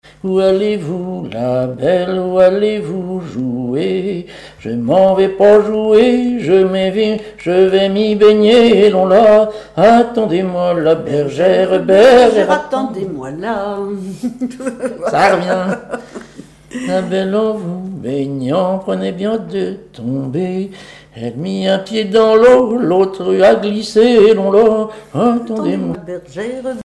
Saint-Pierre-en-Port
Genre laisse
Chansons et commentaires